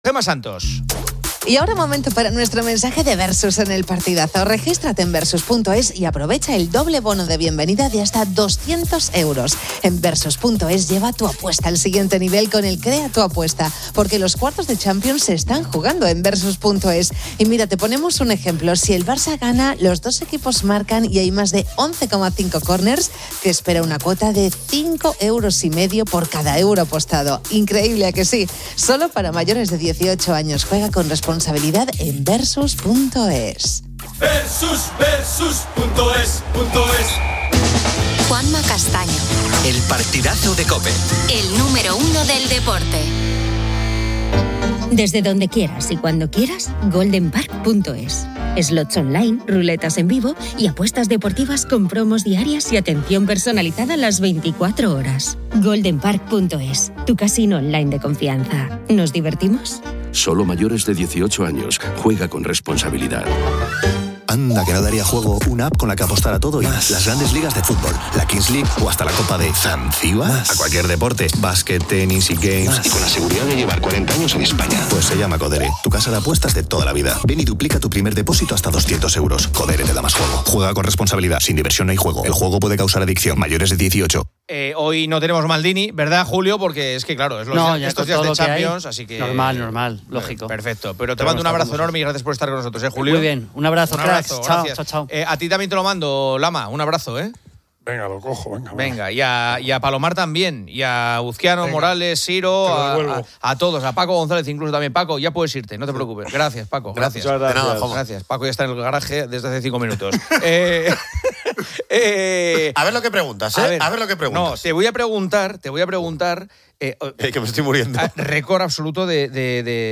A continuación, presenta una entrevista con Carolina Marín, quien explica su retirada del bádminton profesional debido a graves lesiones de rodilla, priorizando su salud para evitar futuras complicaciones.